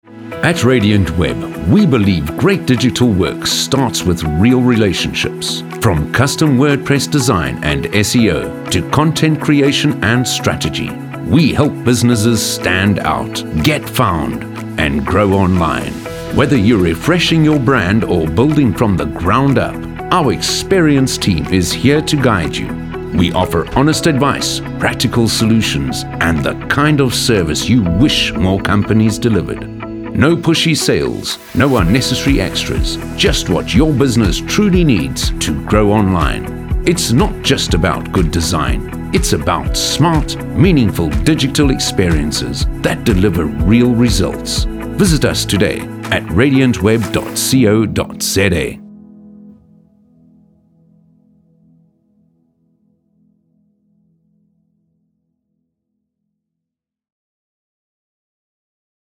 articulate, authentic, captivating, confident, Deep, energetic, friendly
30-45, 45 - Above
Corporate 1